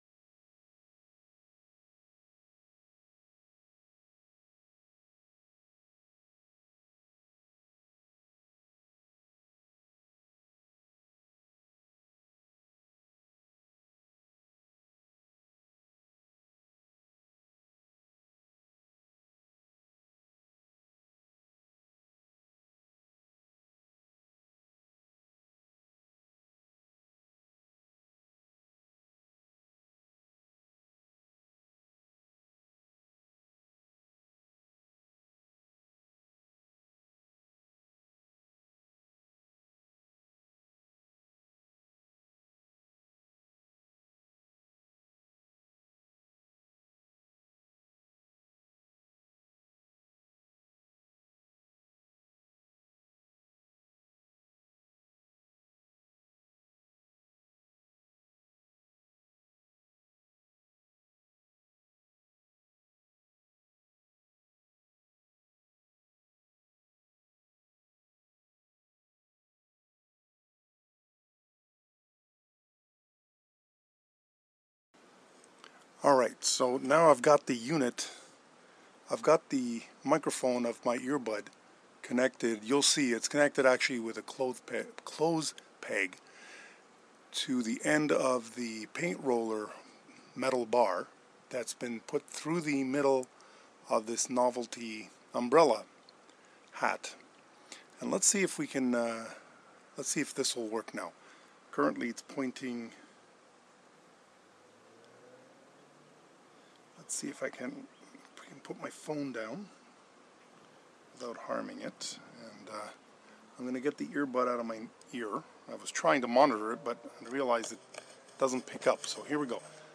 I had to edit the file as the first 1:42 were completely silent.